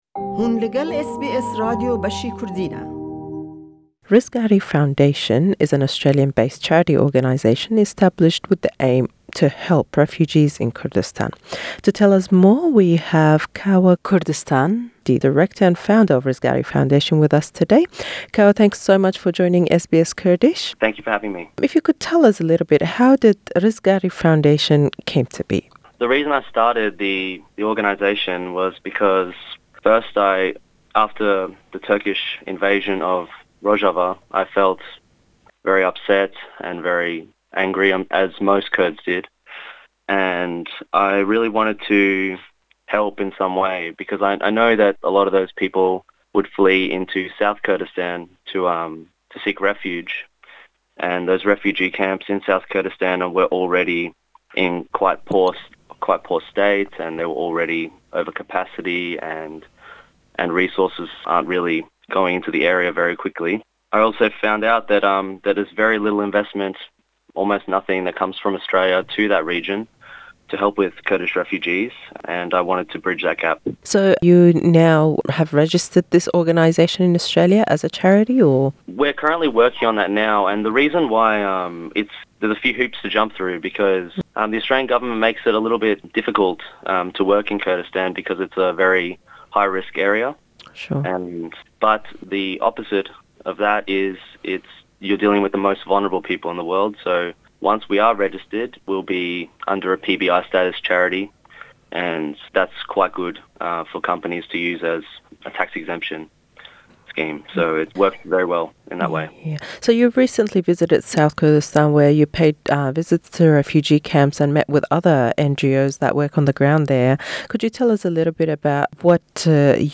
Em hevpeyvîne be zimanî Înglîzî ye.